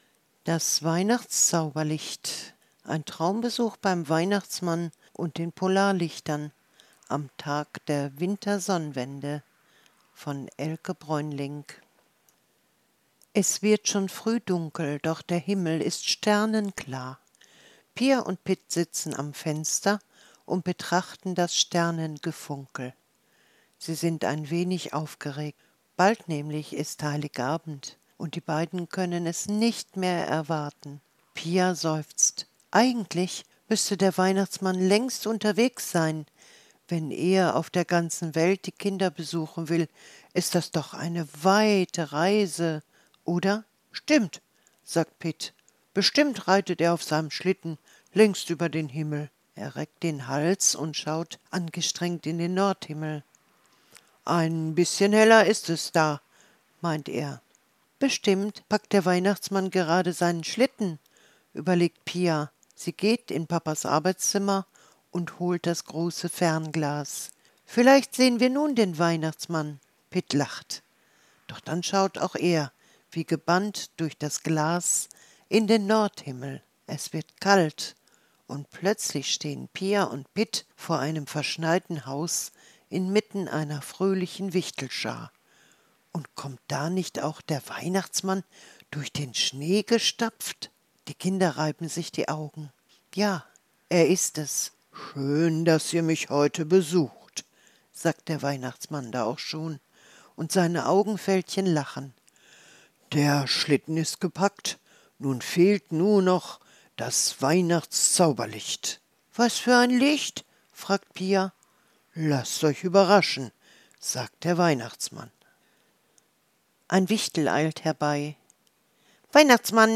Weihnachtsgeschichte für Kinder